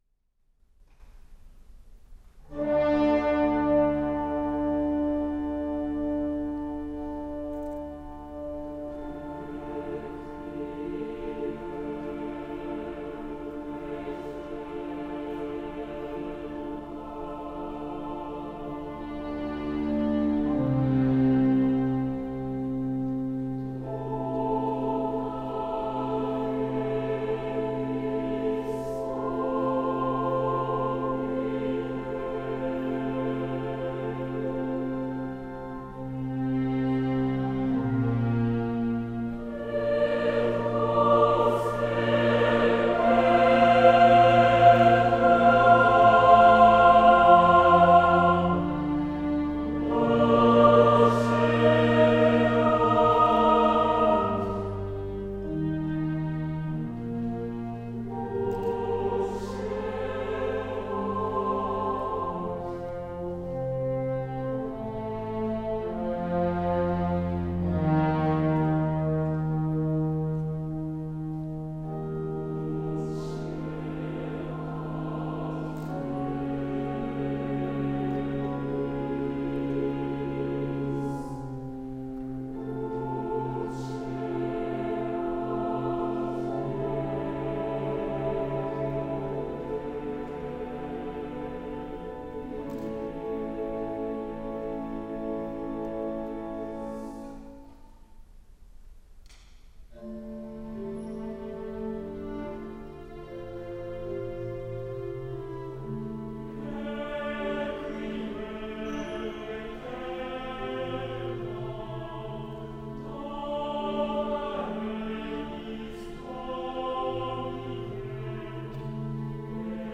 宮古木曜会合唱団・盛岡市営キャラホールでの演奏参加記念 盛岡第四高等学校の第５回定期演奏会第２部のフォーレ作曲レクィエム演奏に、沿岸地区合唱団 への復興支援活動の一環としてご招待をいただき、演奏参加したことを記念するサブページです。
演奏会（第２部）音源収容棚 タイトル名 ファイル名 容量(MB) 時間(秒) 収録状況 レクィエム（フォーレ） part2_FReq_adjHQ 80 2055 客席ほぼ中央に小型録音機を セットしてノーカット収録。 若干音量調整した高音質版。